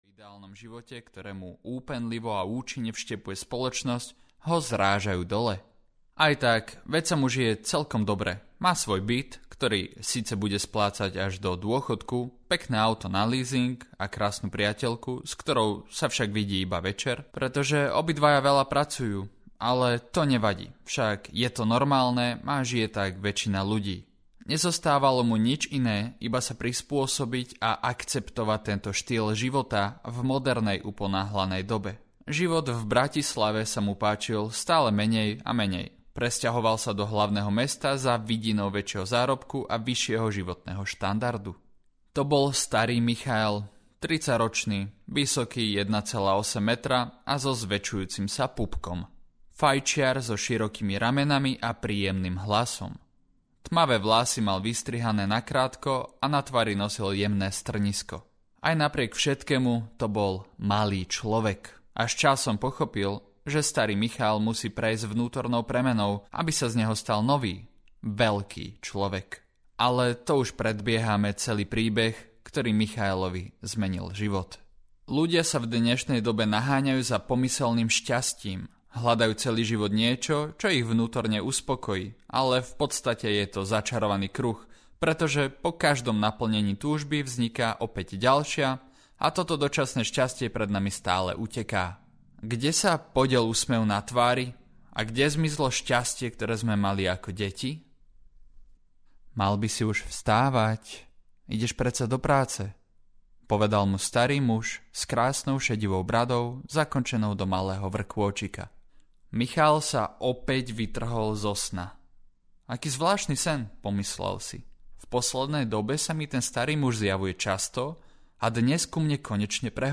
Veľký Človek audiokniha
Ukázka z knihy
velky-clovek-audiokniha